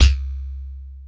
LAZERBASC2-L.wav